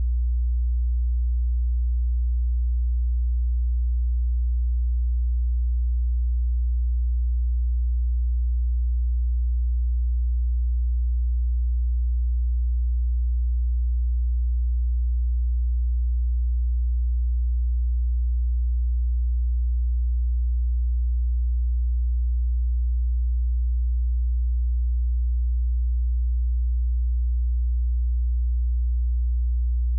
60Hz_-19.dB.wav